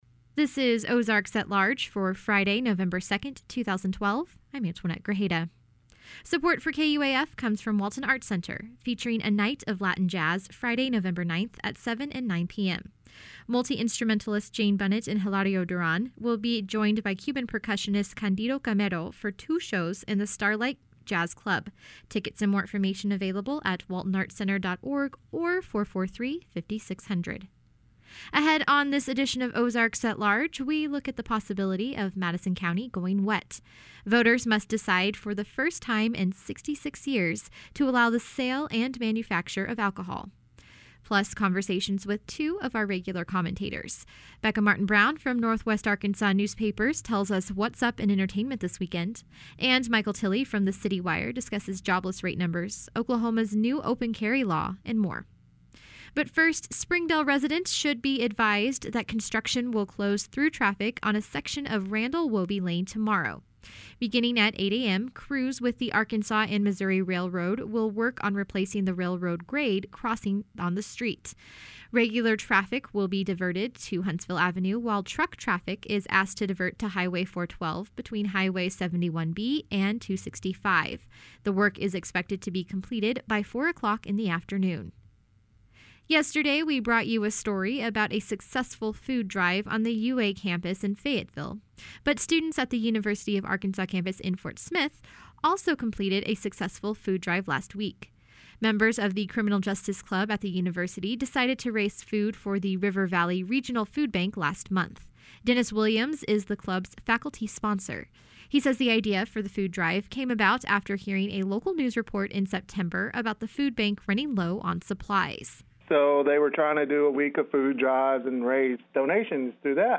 Audio: oalweb110212.mp3 Ahead on this edition of Ozarks at Large, we look at the possibility of Madison county going wet. Plus, conversations with two of our regular commentators.